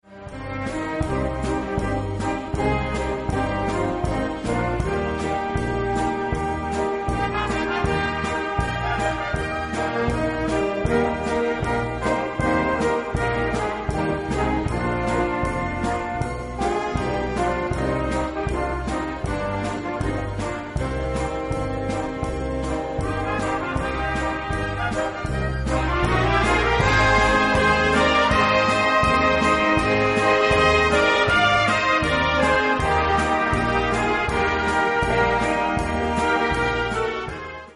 Gattung: Evergreen
Besetzung: Blasorchester